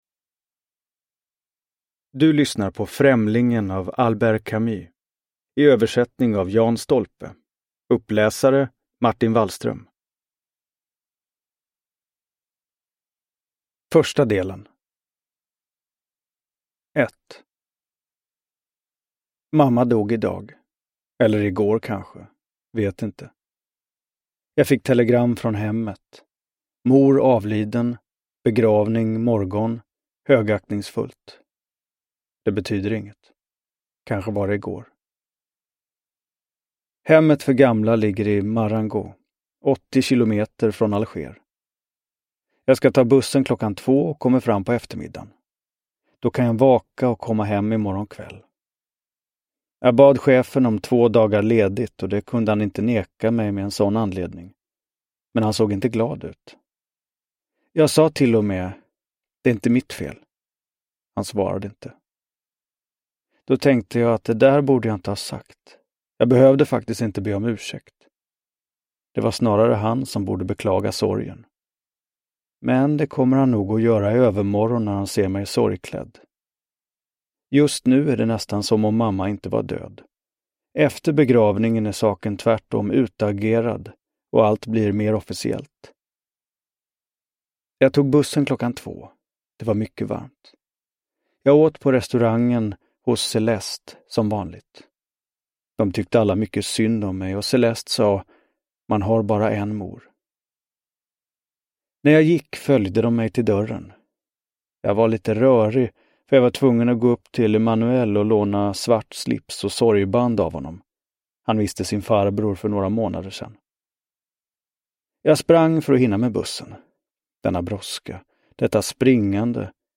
Främlingen – Ljudbok – Laddas ner
Uppläsare: Martin Wallström